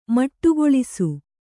♪ maṭṭugoḷisu